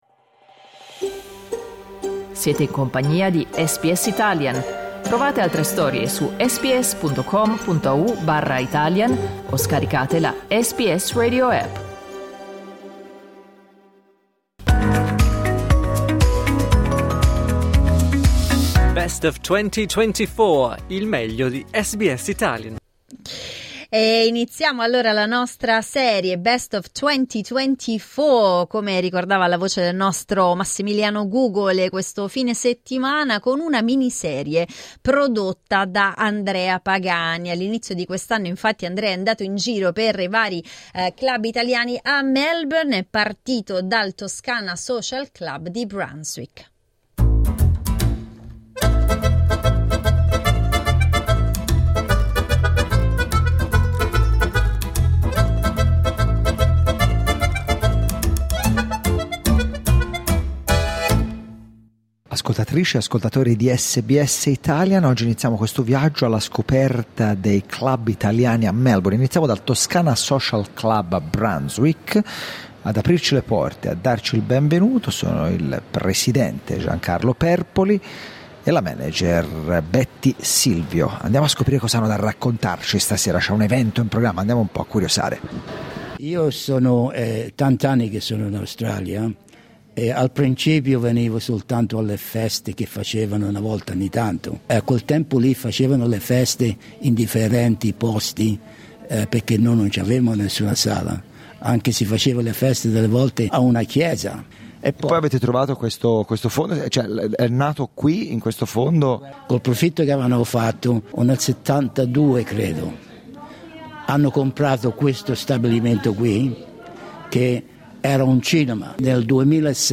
Dai nostri archivi, una delle nostre visite ai club italiani di Melbourne: in questo episodio siamo andati a Brunswick a celebrare la toscanità.